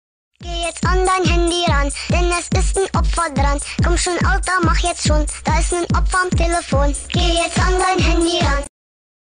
Kategorie: Klingeltöne